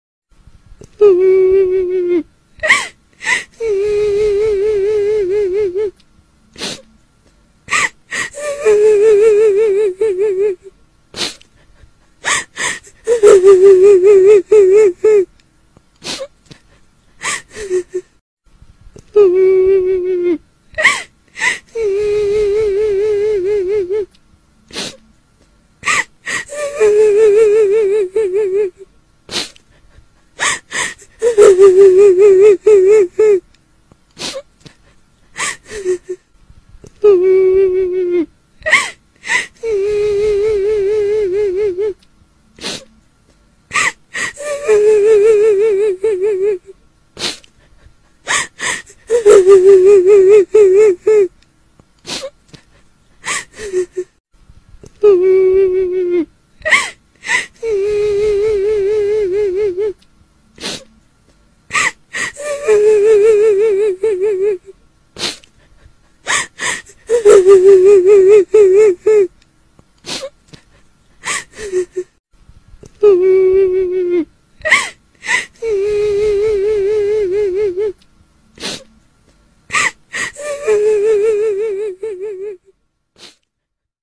Nada dering Kuntilanak MENANGIS
Suara Kuntilanak mp3 pendek Nada dering Kuntilanak
nada-dering-kuntilanak-menangis-id-www_tiengdong_com.mp3